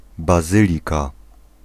Ääntäminen
France: IPA: [ba.zi.lik]